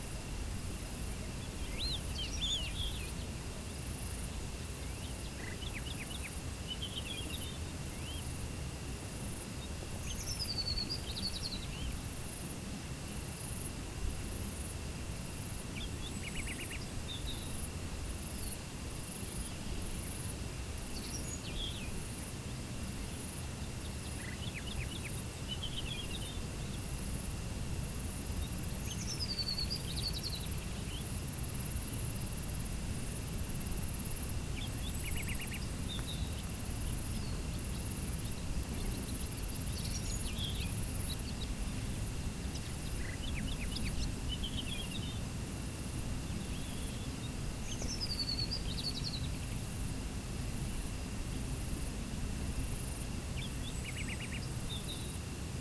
meadow_evening.ogg